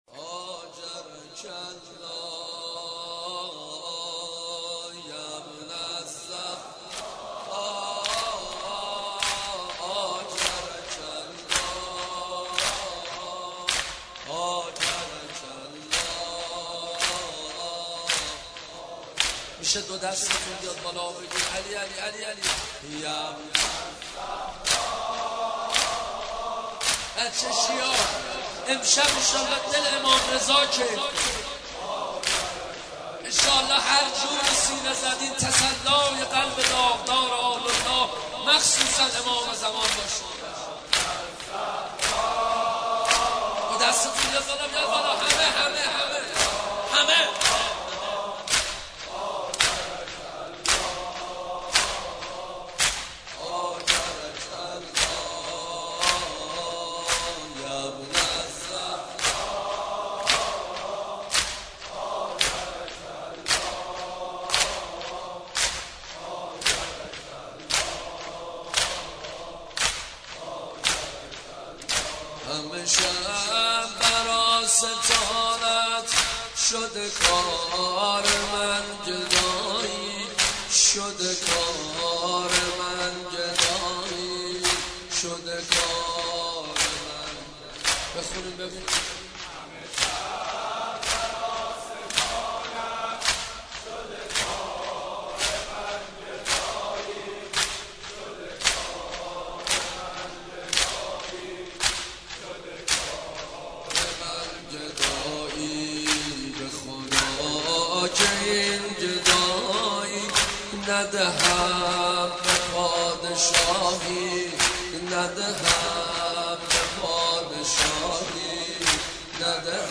مداحی و نوحه
[سینه زنی زمینه]